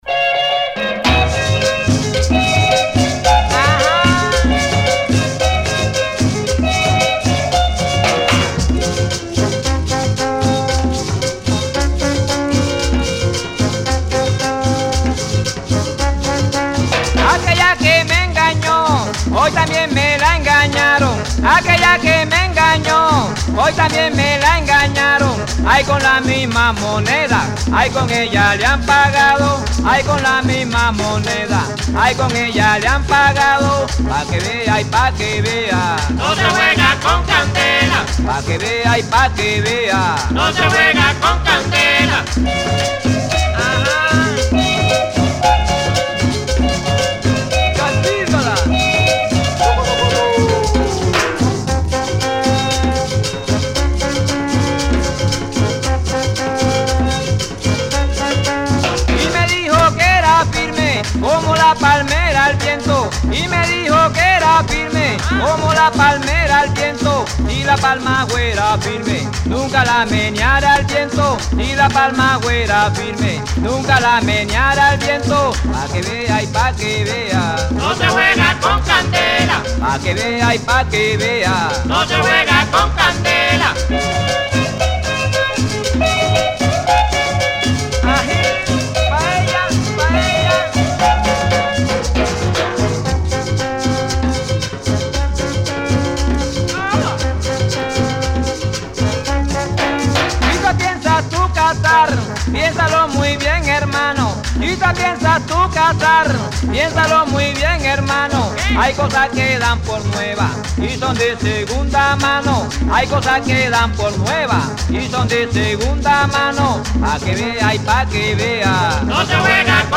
starting with this Cumbia microgenre from Colombia